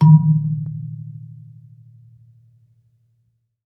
kalimba_bass-D#2-mf.wav